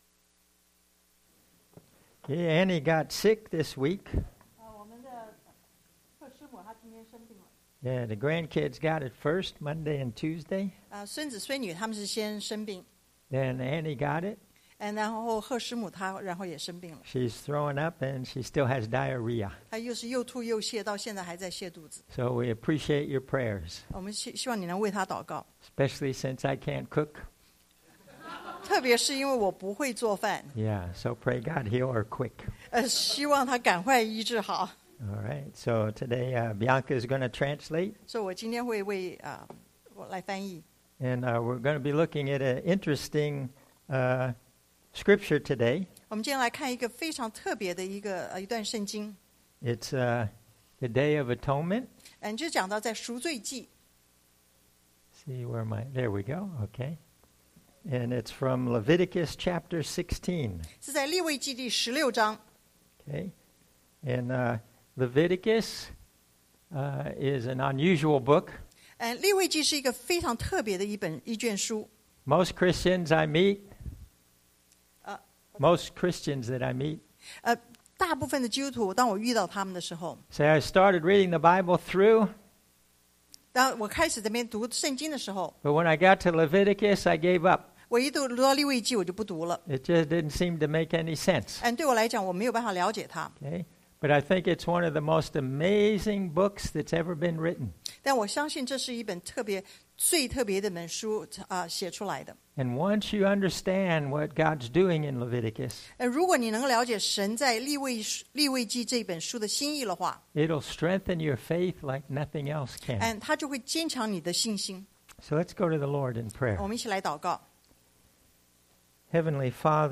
The Glory of Christ in The Day of Atonement 贖罪日中基督的榮耀 English Sermon
Service Type: Sunday AM